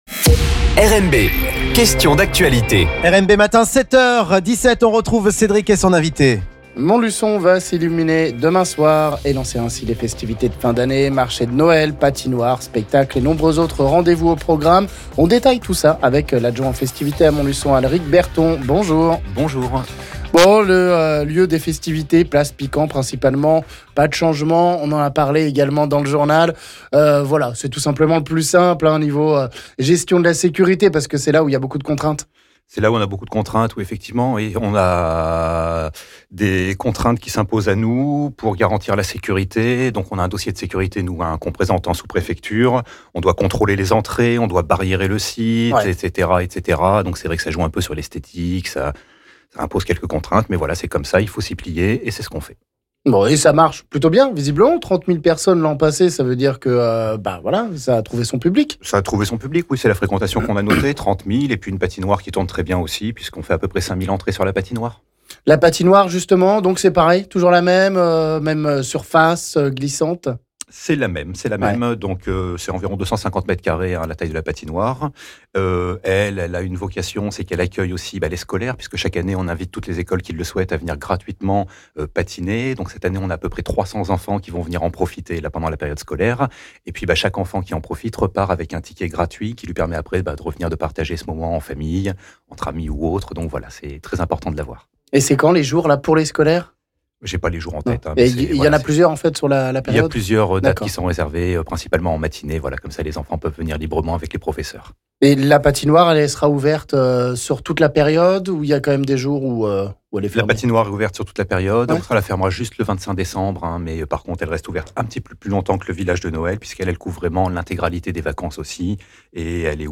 L'adjoint montluçonnais aux festivités nous présente le programme des animations prévues pour Noël